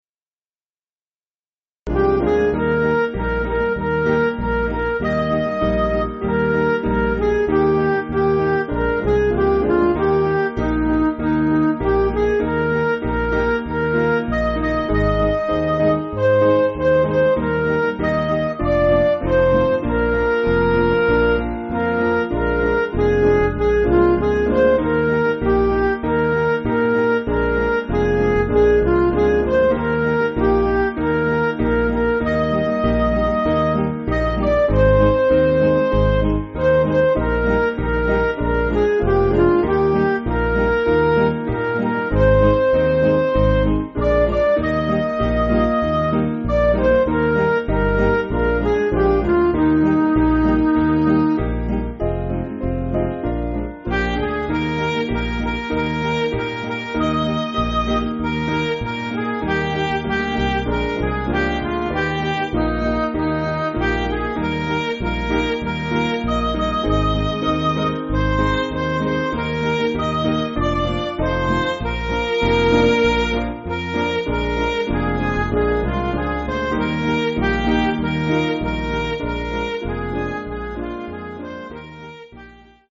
Piano & Instrumental
(CM)   3/Eb
Midi